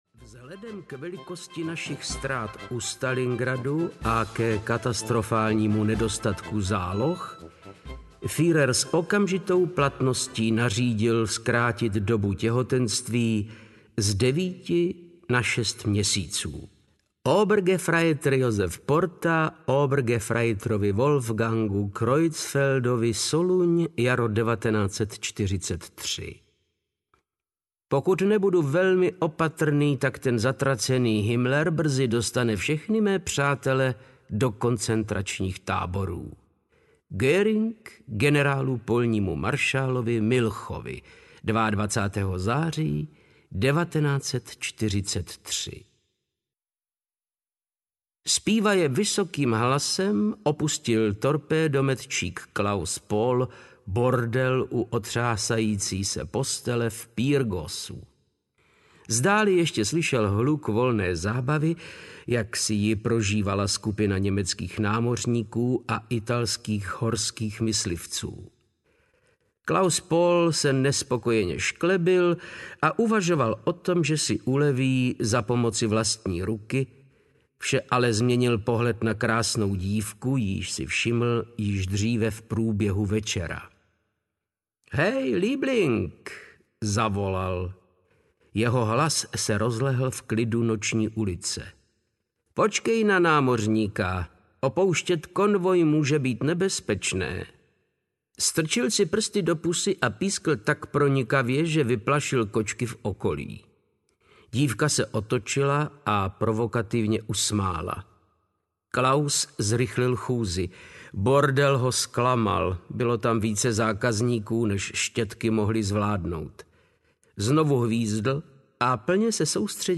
Krvavá cesta na smrt audiokniha
Ukázka z knihy
• InterpretVáclav Knop